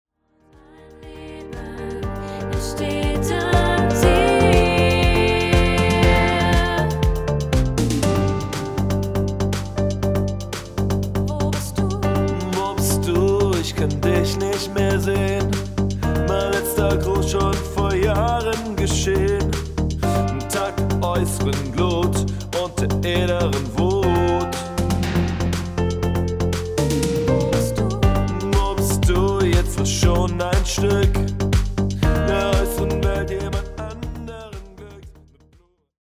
80s Drums?
Man kann es drehen und wenden wie man will, am Ende ist und bleibt es ein STINKNORMALER Gated Reverb!